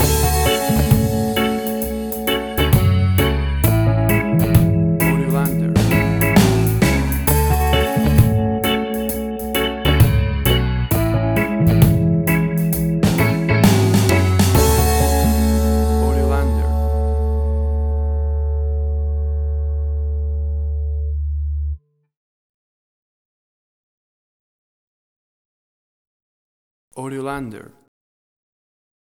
A chilled and relaxed piece of smooth reggae music!
Tempo (BPM): 66